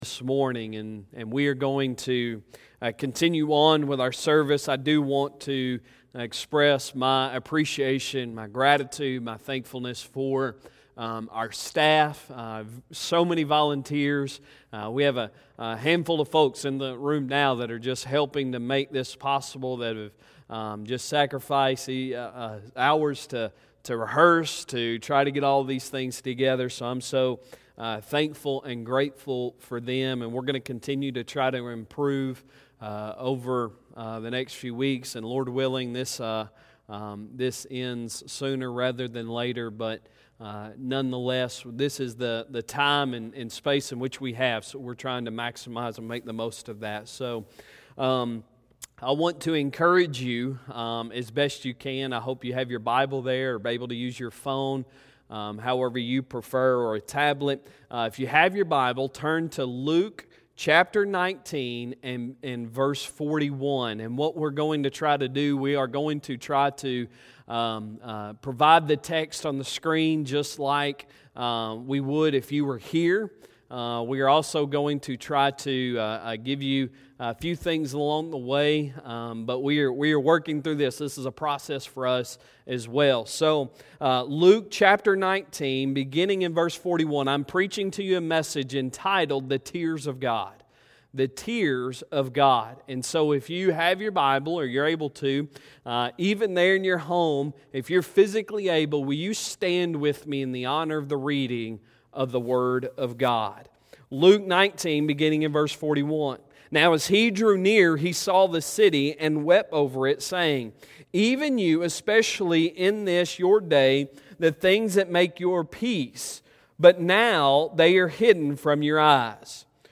Sunday Sermon March 22, 2020